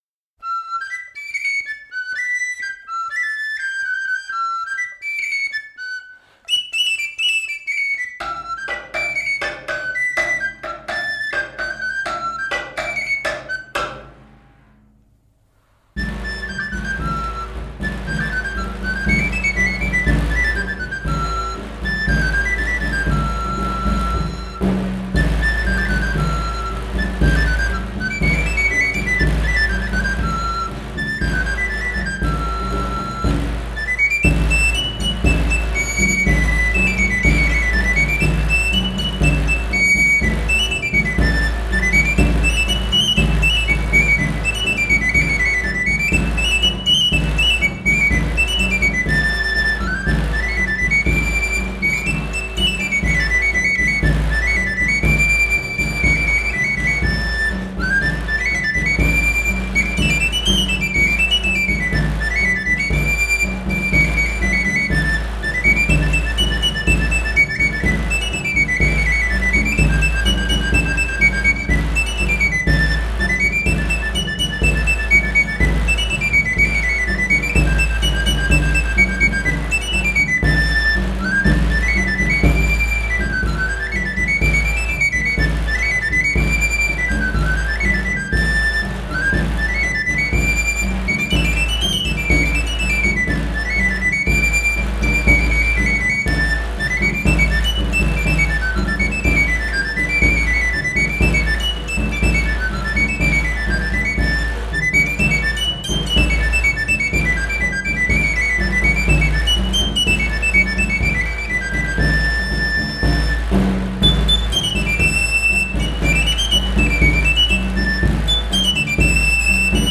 土着と洗練のフォークミュージック！フランス、プロバンス地方独自の小太鼓と笛による伝統音楽！